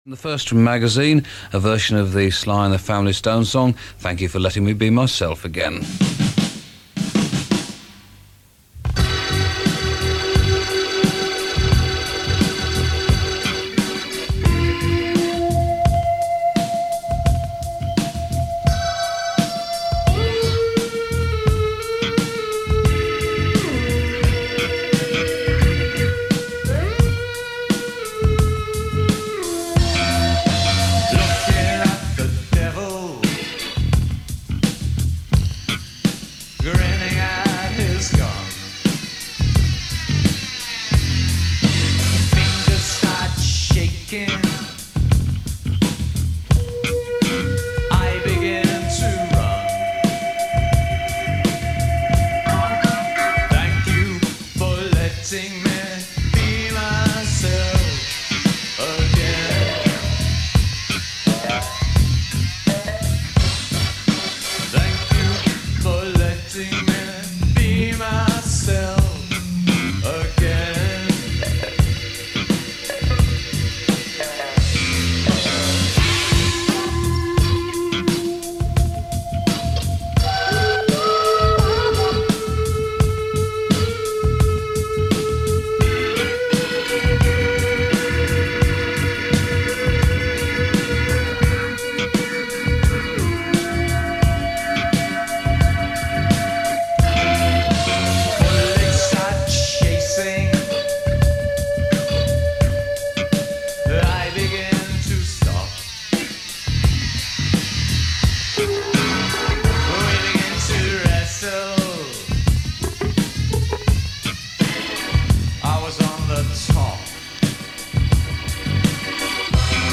the New Wave sessions.
on bass
on drums
New Wave with an Avant-garde twist